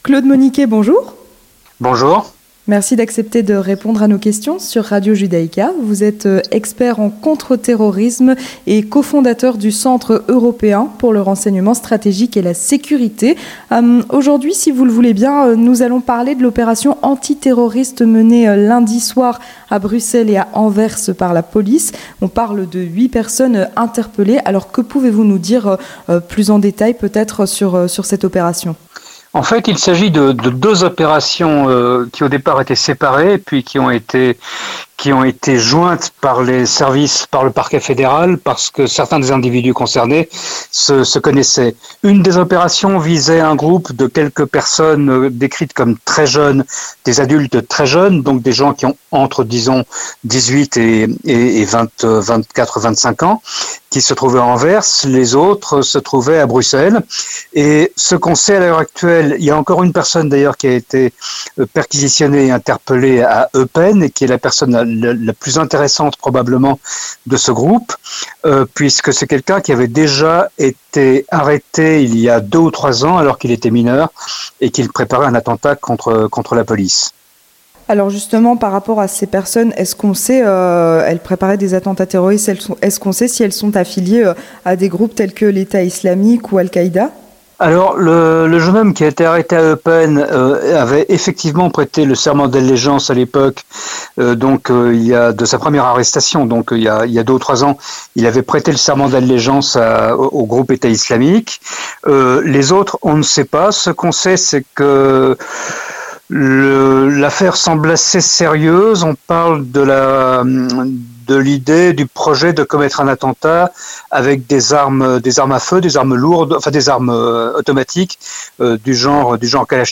Entretien du 18h - Opération antiterroriste à Bruxelles et à Anvers